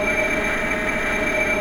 Mystical_EnergyLoop27.wav